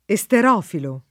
[ e S ter 0 filo ]